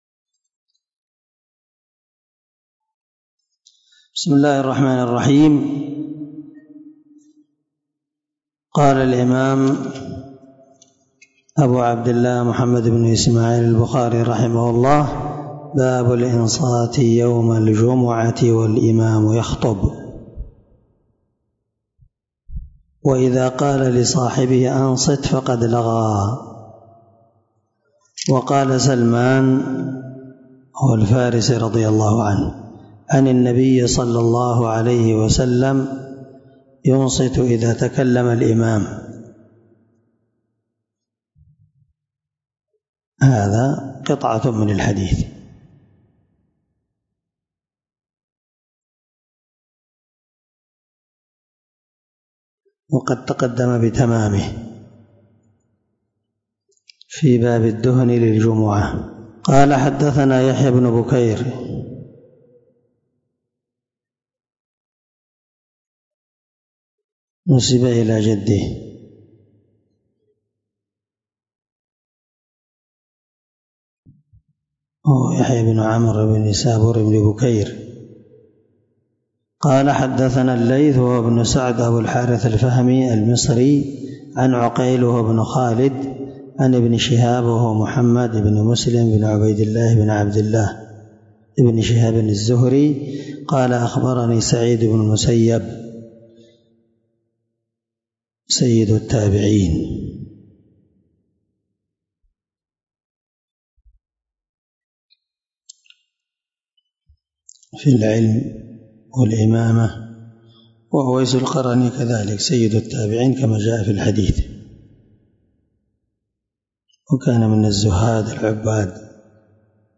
578الدرس 30شرح كتاب الجمعة حديث رقم (934)صحيح البخاري
دار الحديث- المَحاوِلة- الصبيحة.